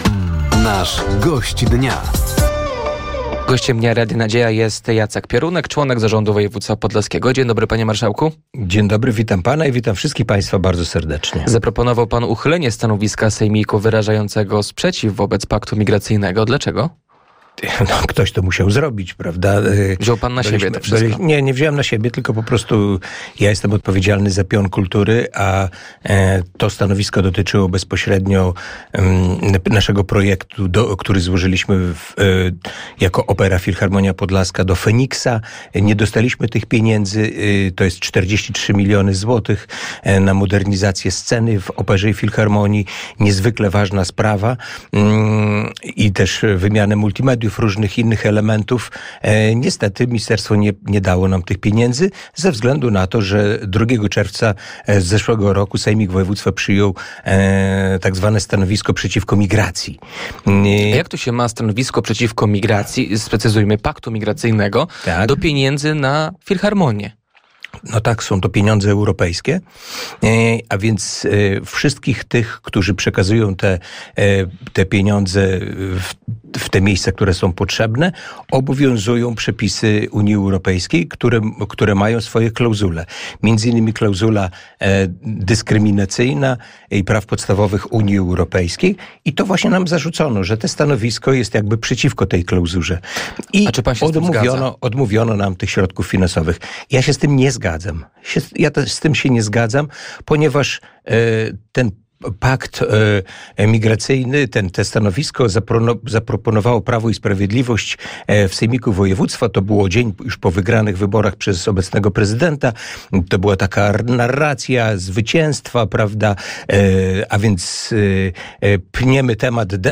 Gościem Dnia Radia Nadzieja był członek zarządu województwa podlaskiego Jacek Piorunek. Tematem rozmowy było uchylenie stanowiska sejmiku wyrażającego sprzeciw wobec paktu migracyjnego, nowy sprzęt w Szpitalu Wojewódzkim w Łomży oraz nowa baza łomżyńskiego PKS-u, która powstanie na terenie gminy Łomża.